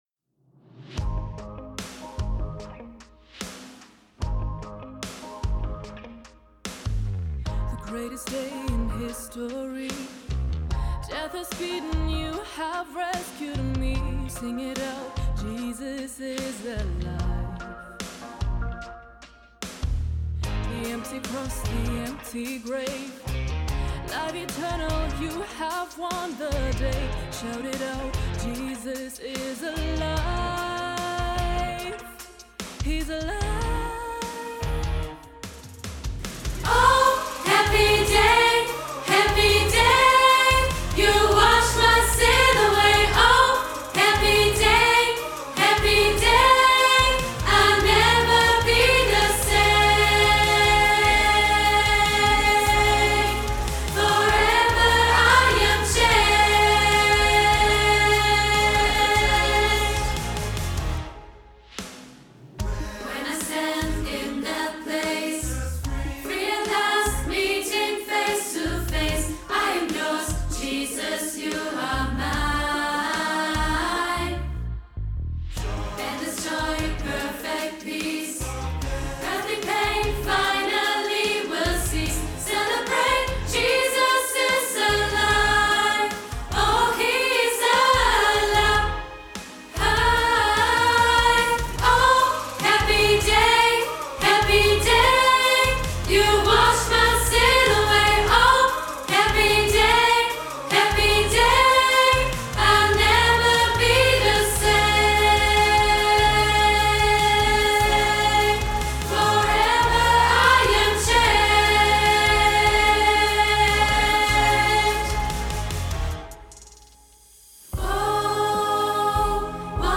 Audiospur Sopran